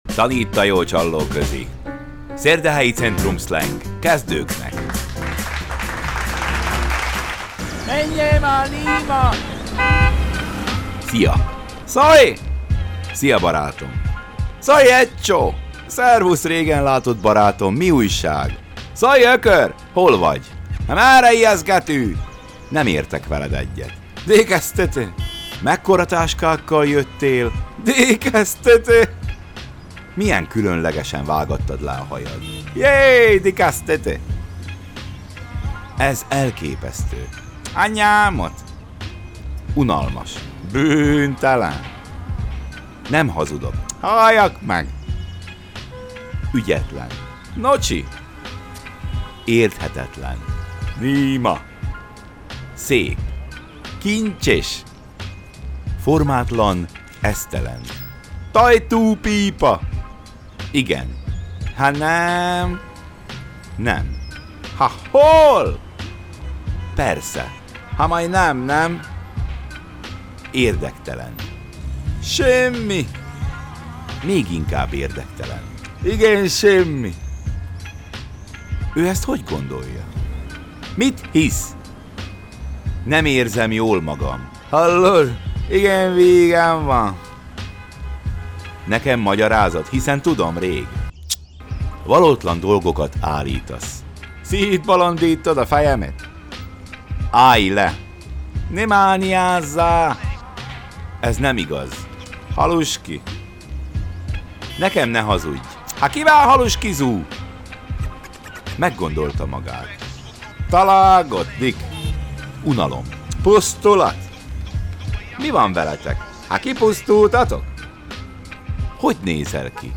A sorozatunk legjobb részei most egy válogatásban hallhatók. Ebben a csaknem hétperces összeállításban Dunaszerdahely belvárosának jellegzetes beszéde szólal meg, így minden eddig rejtélyesnek tűnő kifejezés végre értelmet nyer.